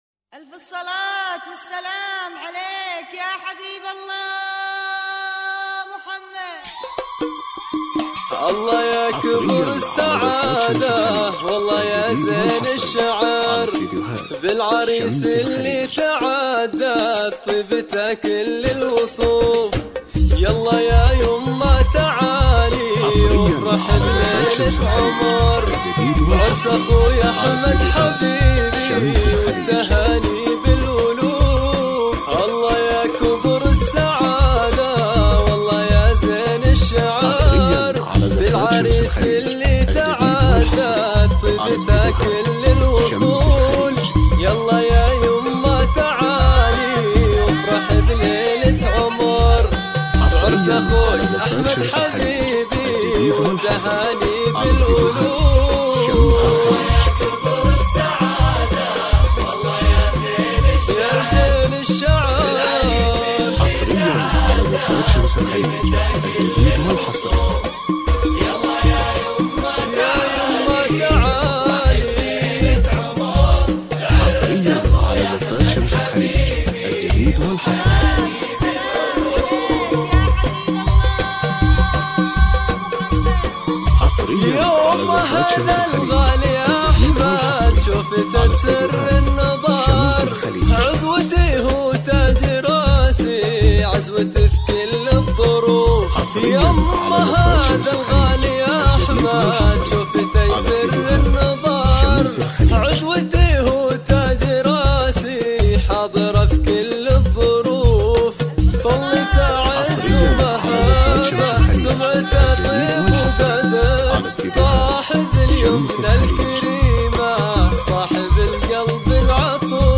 زفات دفوف